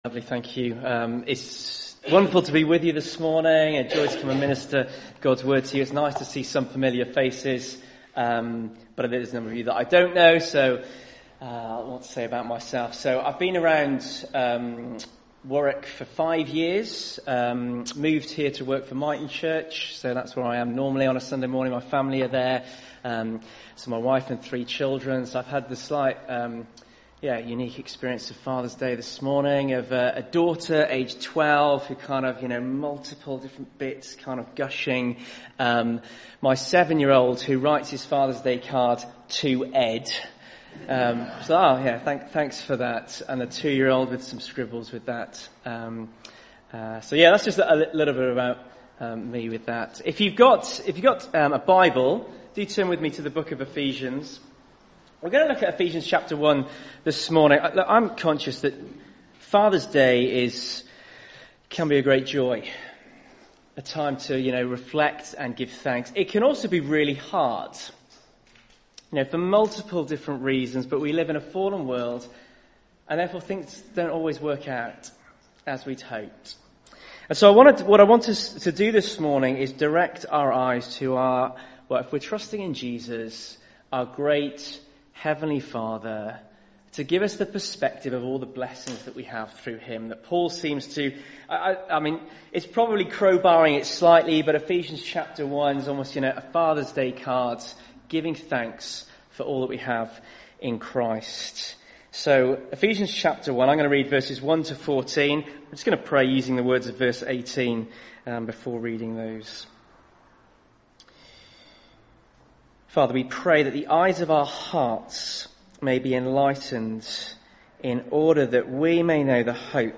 19 June 2022 Play audio only using the soundbar above View Sermons from all the years!